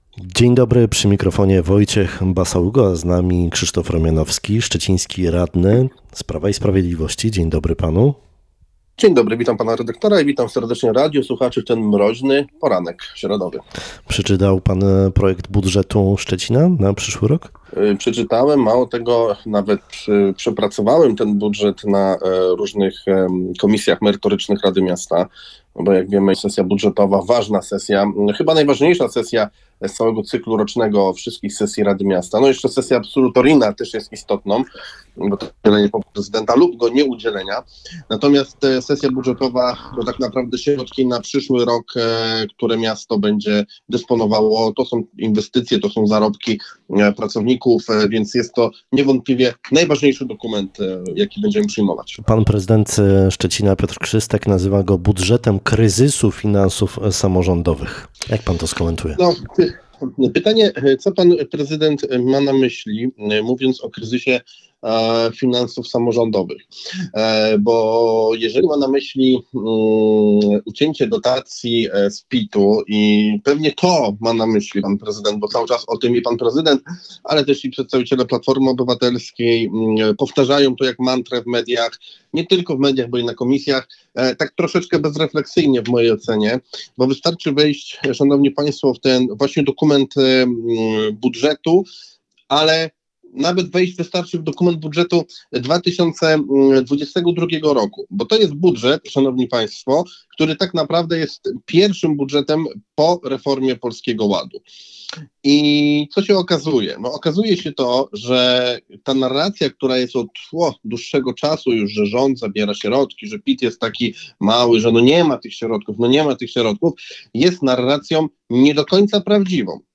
Szczeciński radny Prawa i Sprawiedliwości był w środę gościem Rozmowy Dnia w Twoim Radiu. Odpowiadał na pytania dotyczące trudnego przyszłorocznego budżetu Szczecina i stanu finansów samorządu. Nie zgadza się także z zarzutem, że polityka rządu doprowadziła do uszczuplenia budżetów samorządów.